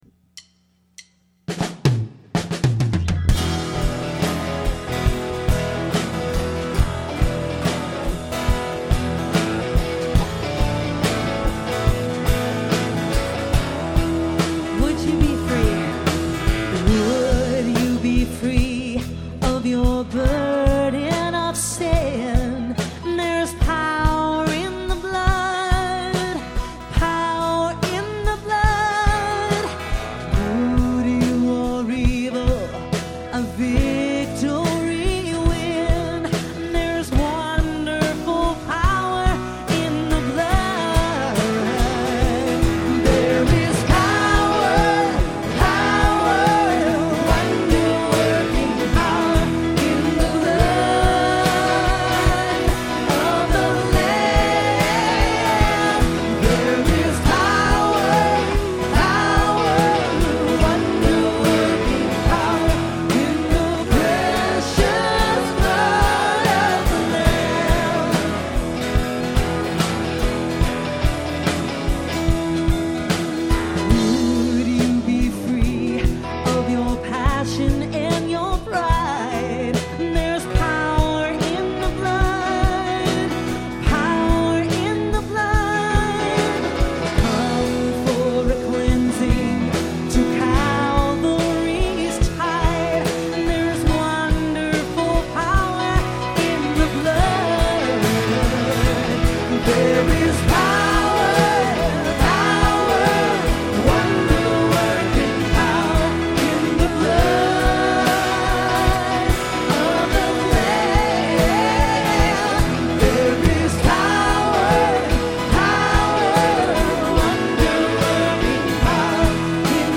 Performed live on Easter at Terra Nova - Troy on 3/23/08.